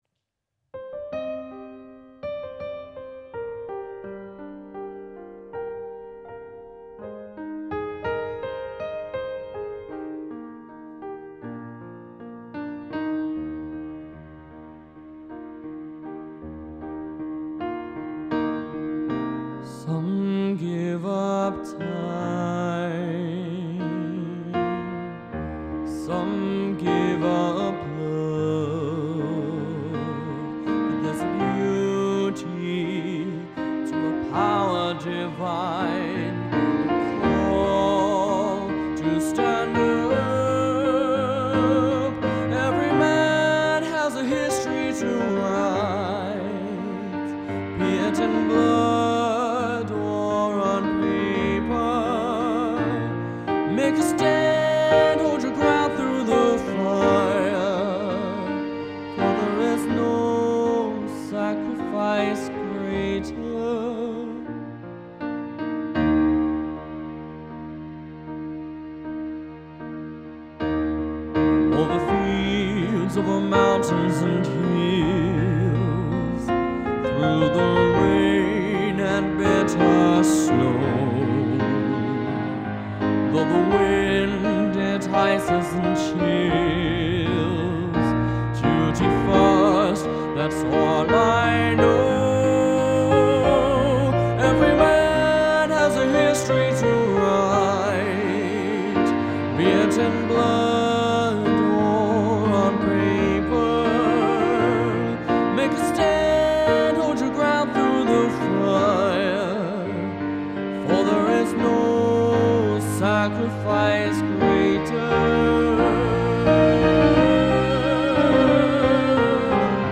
A Musician, Singer/Pianist with Talent Beyond His Years